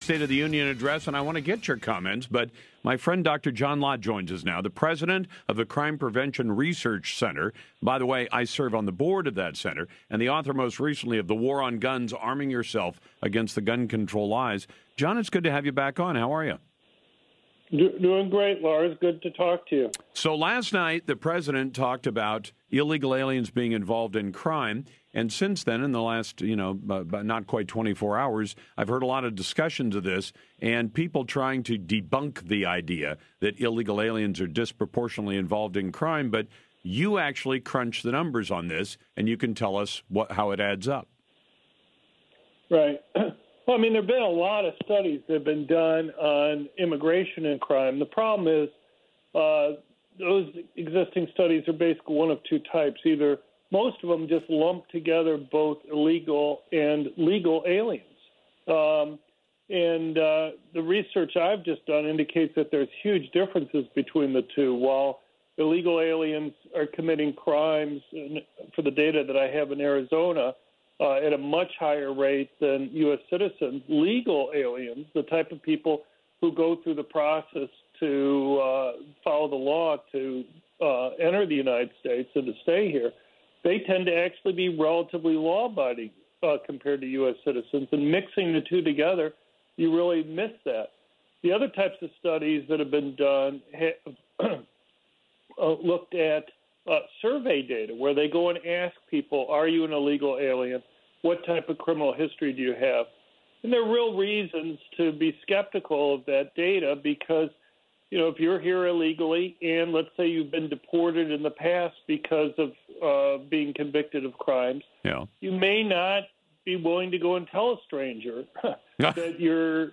media appearance
Dr. John Lott talked to Lars Larson on his national radio about President Trump’s State of the Union address regarding illegal immigrants.  They talked about our new research on illegal immigrants and crime.